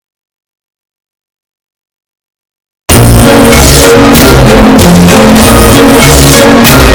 Old Man Mumbling